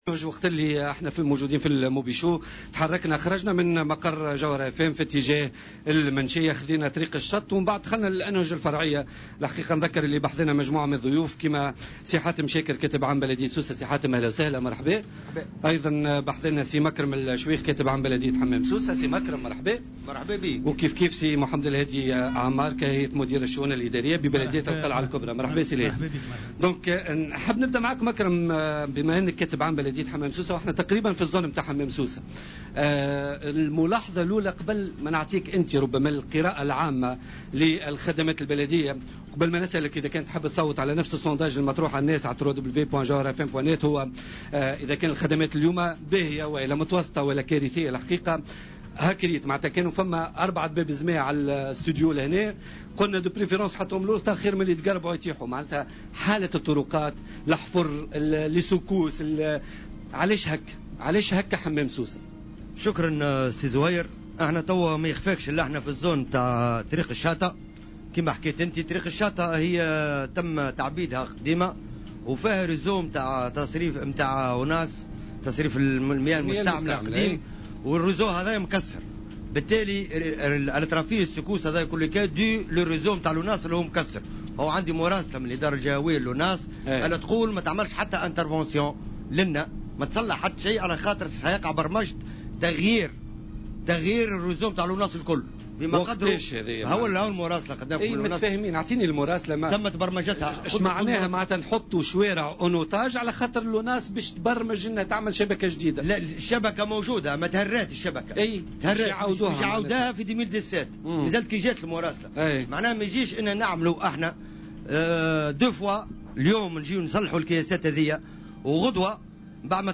مداخلة له اليوم في برنامج